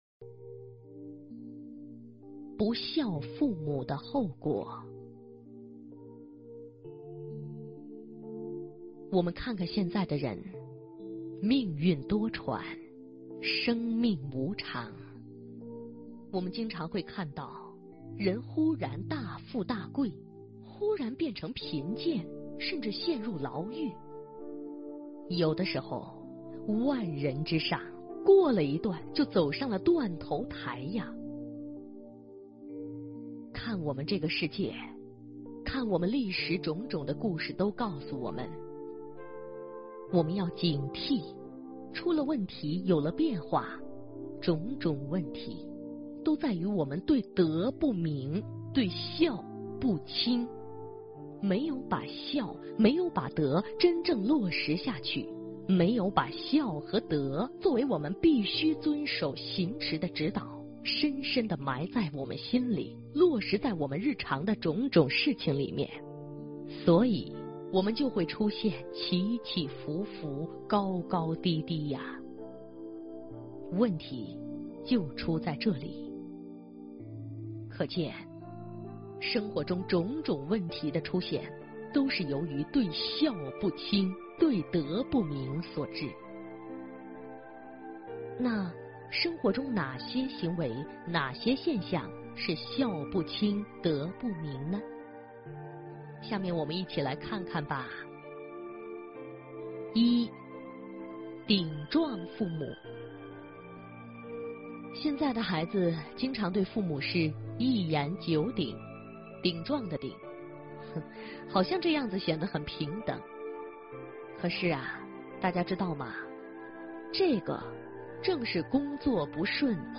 不孝父母的后果--有声佛书